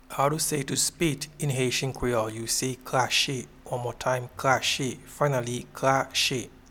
Pronunciation and Transcript:
to-Spit-in-Haitian-Creole-Krache-1.mp3